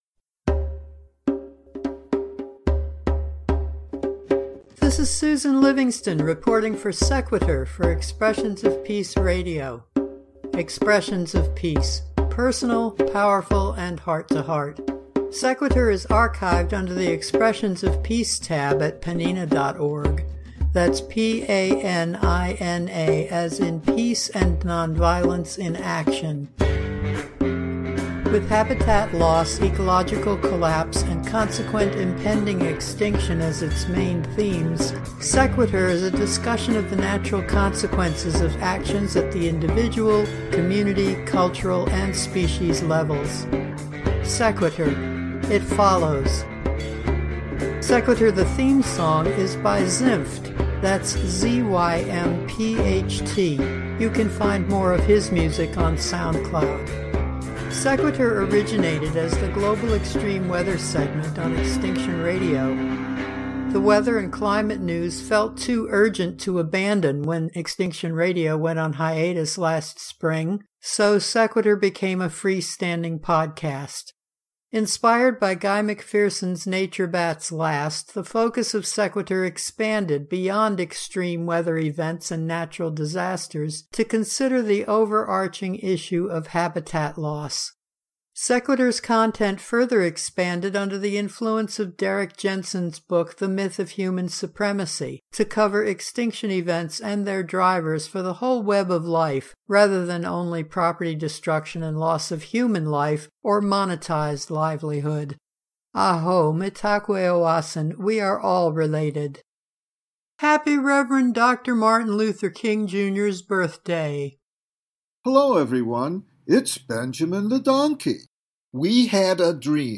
We’ll start with the history of the MLK Day holiday and topical music recorded live in the late ’60s – no retakes like in the studio, and the sound quality is… well, old.